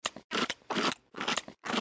monster_chewing.mp3